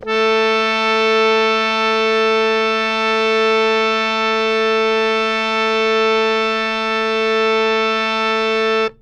harmonium
A3.wav